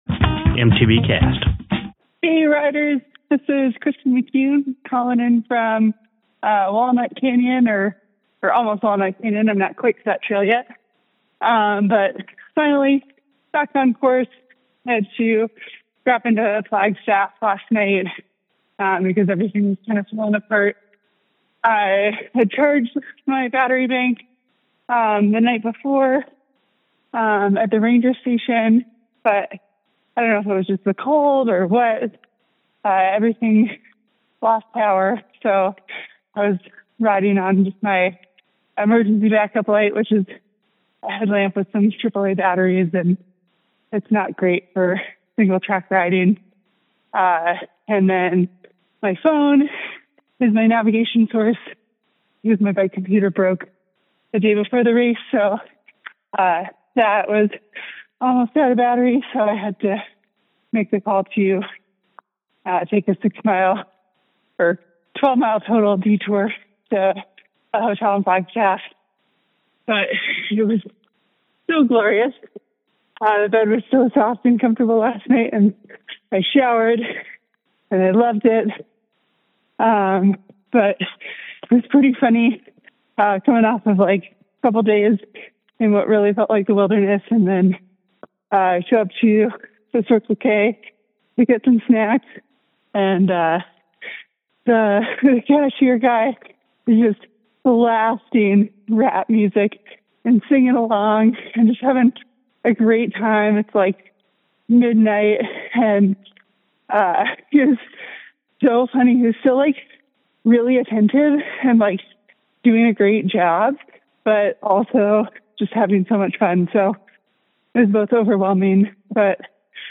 Posted in AZT25 , Calls Tagged AZT25 , bikepacking , Call Ins , calls , endurance racing permalink